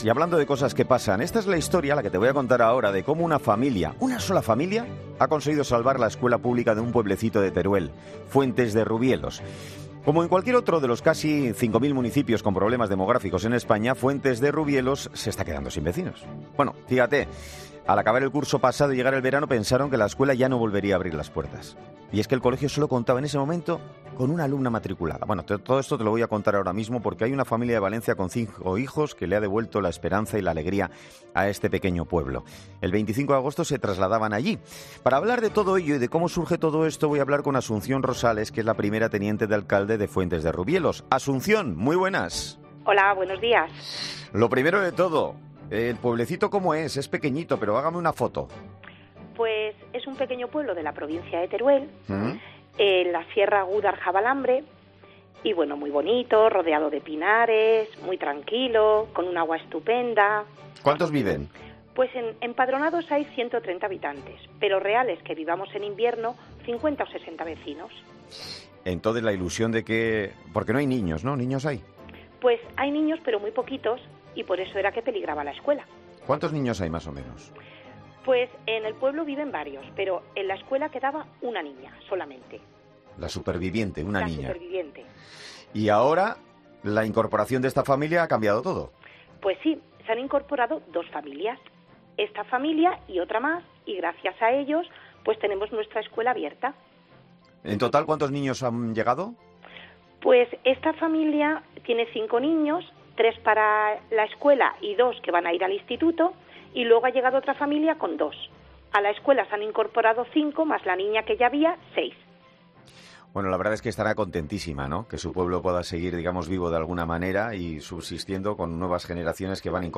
Teniente de Alcalde de Fuentes de Rubielos: "Cuando la escuela se cierra, el pueblo se muere"